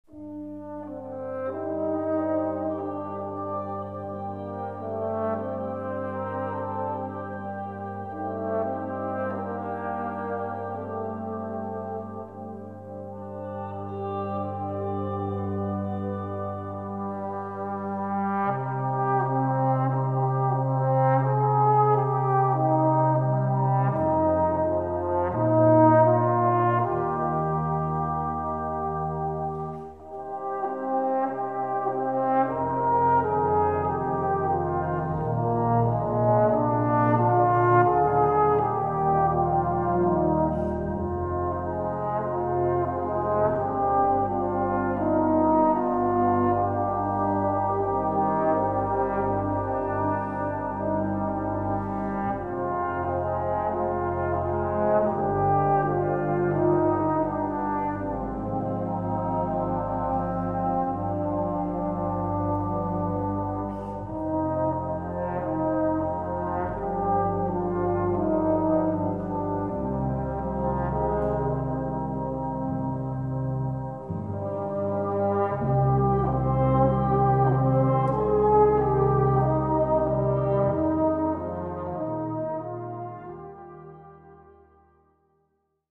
Répertoire pour Brass band - Brass Band